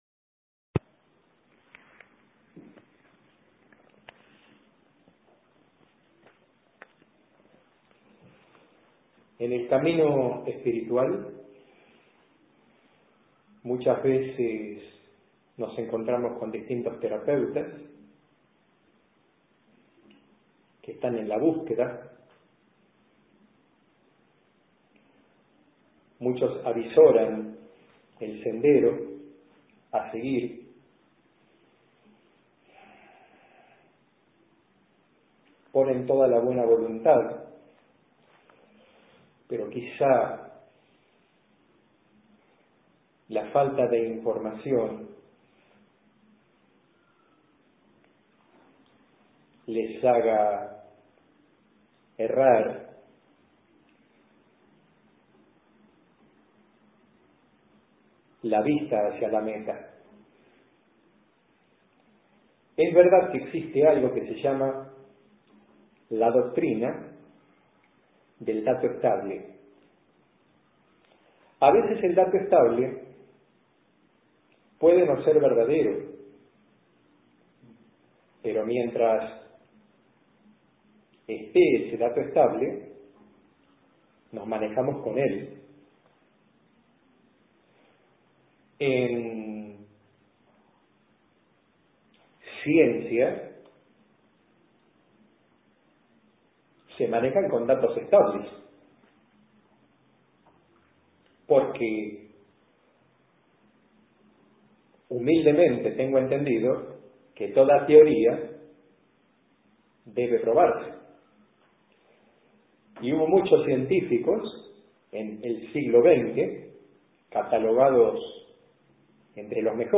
Charla en MP3 (3.007 KB)